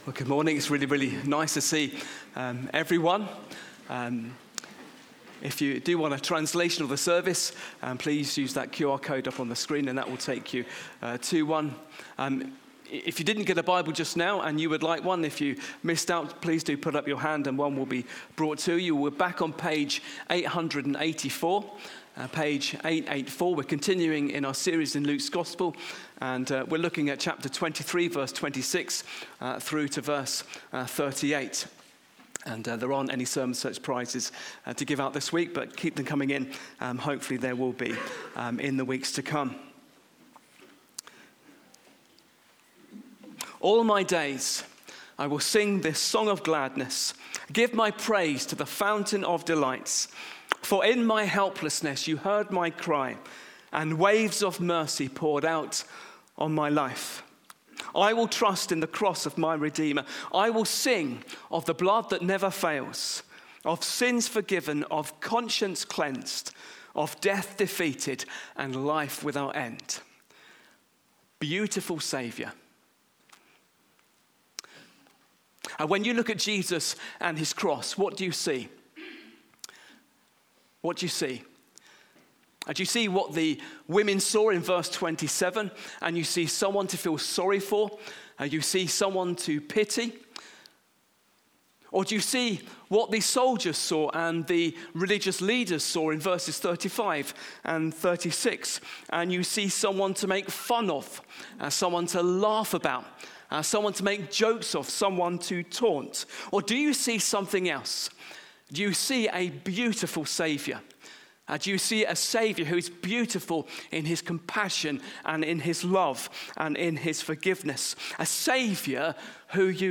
Hillfields Church Coventry | Sermons